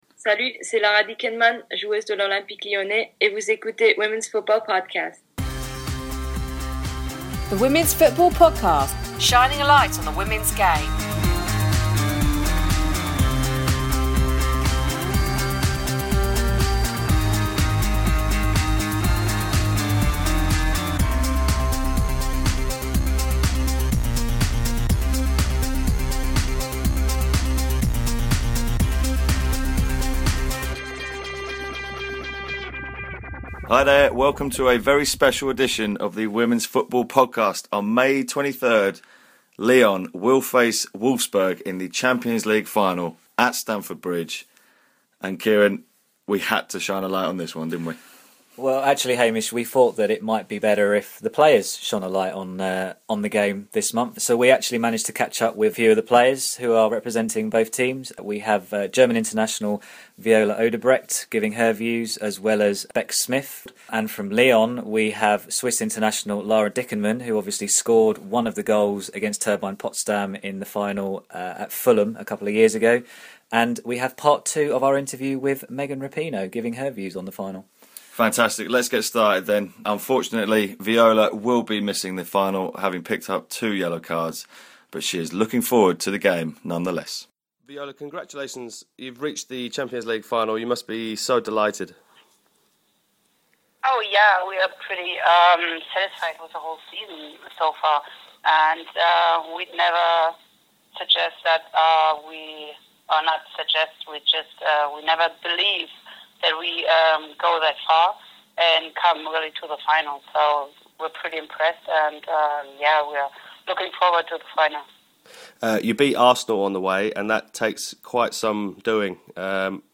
Podcast features interviews with players from both clubs, who give their views on the showpiece at the home of Chelsea FC.